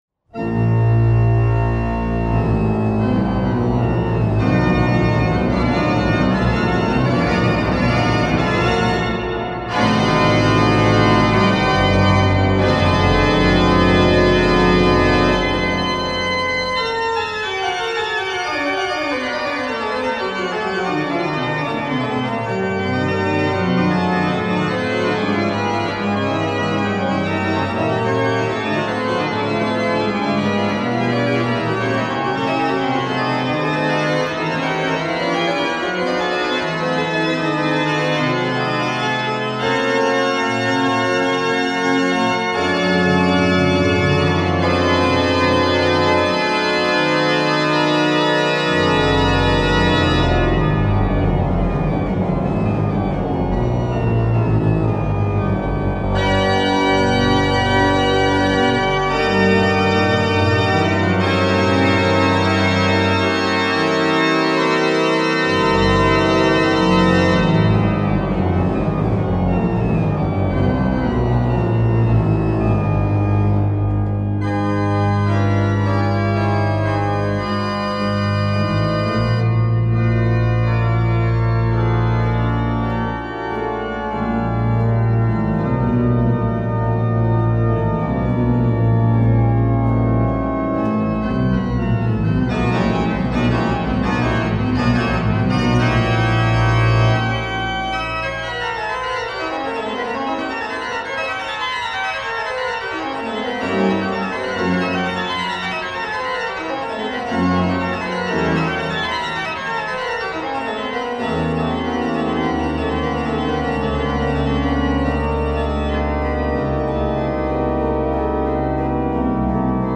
Organy Friedricha Ladegasta z 1876 roku.